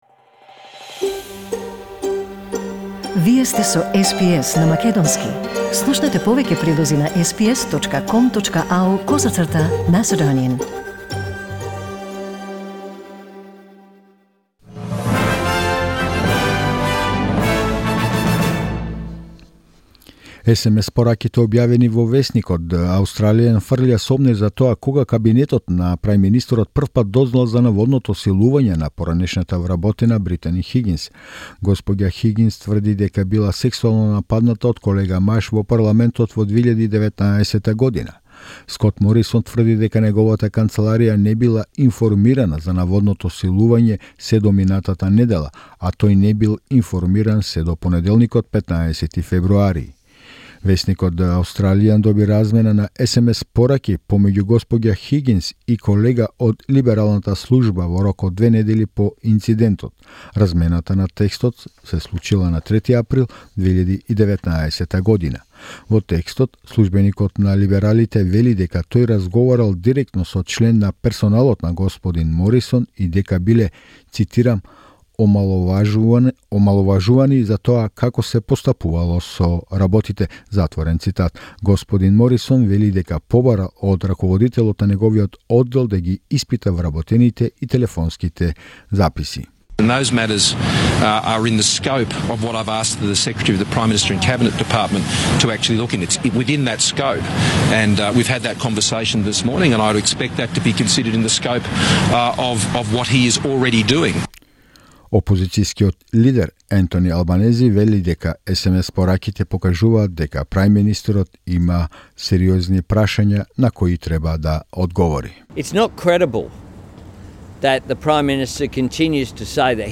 SBS News in Macedonian 19 February 2021